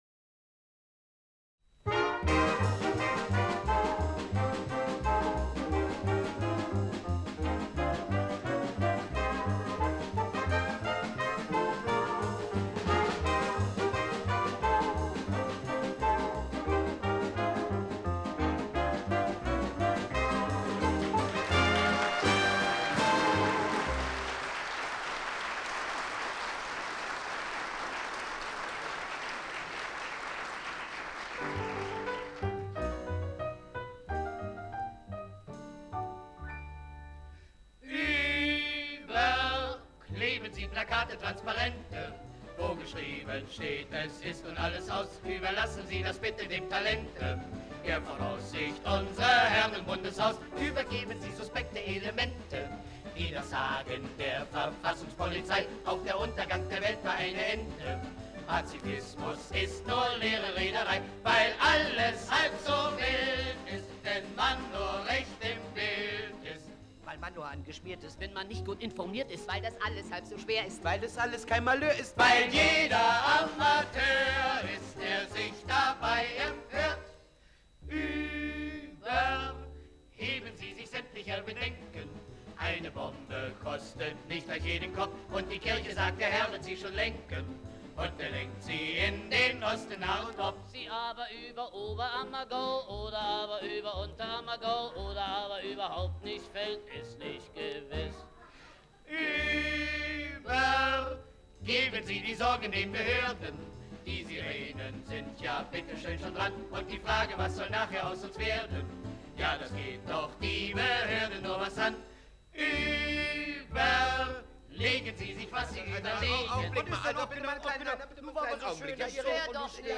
Kabarett
Dennoch ermöglichen die jeweils erschienen Langspielplatten einen Eindruck der Umgangsweise der beiden Ensembles mit dieser schwierigen Thematik. Ein Ausschnitt des Programms ''Überleben Sie mal!'' kann hier heruntergeladen werden.